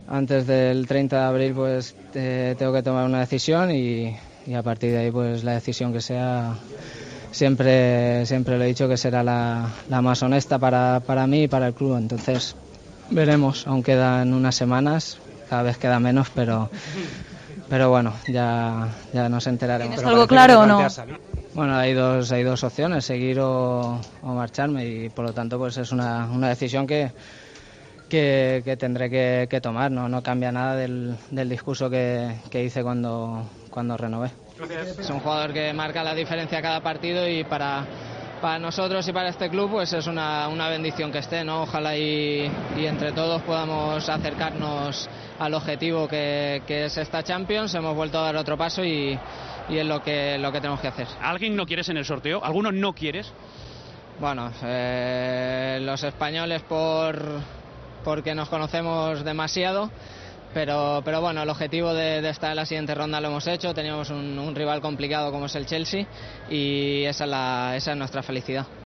Aún quedan unas semanas", ha dicho Iniesta en la zona mixta del Camp Nou.